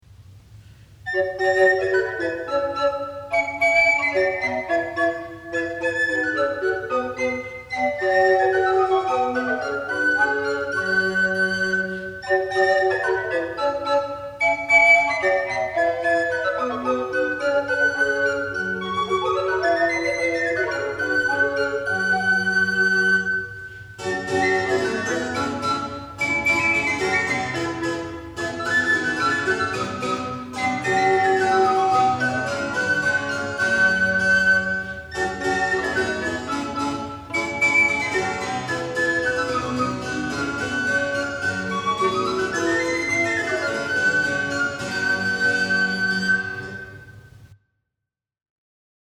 An Italian frottola sandwiched between two of Juan del Ensina’s romances in the ‘Cancionero de Palacio’ .
This is an instrumental arrangement.
| Chamber Consort Instruments 'Food of Love' 1982